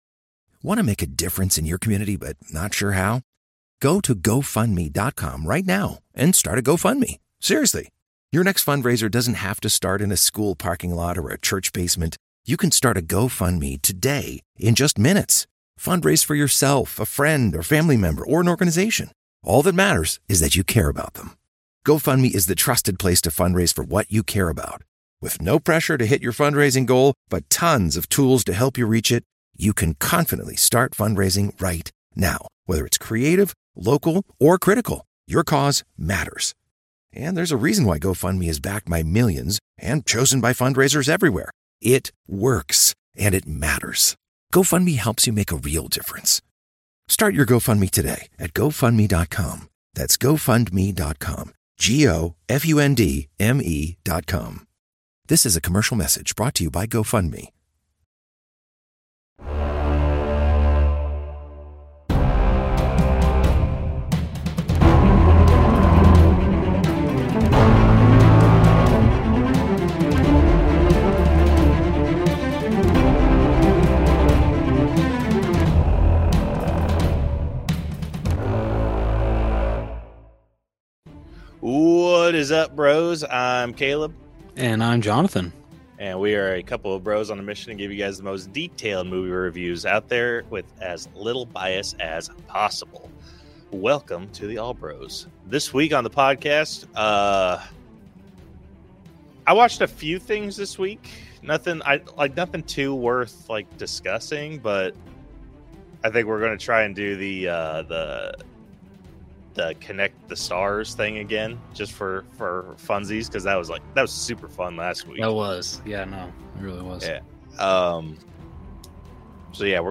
Apologies for the rain, it happens.